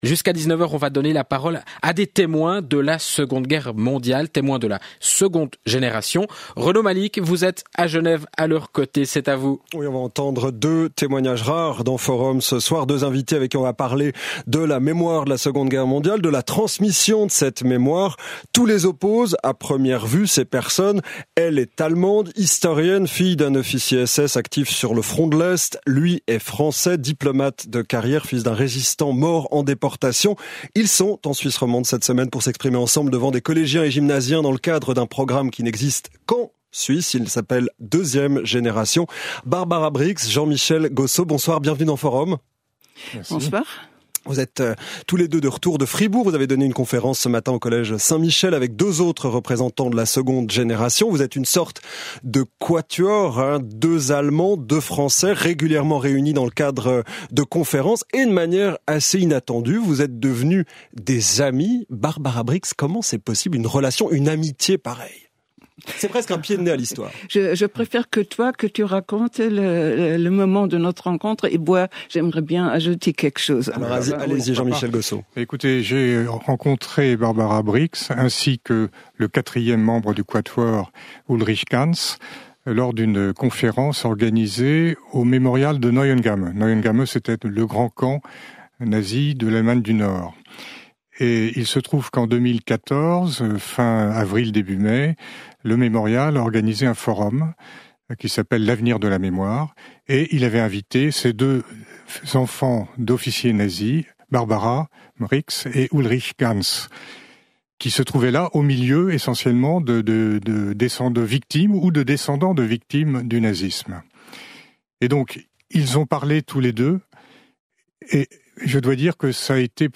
Les orateurs interviewés par la RTS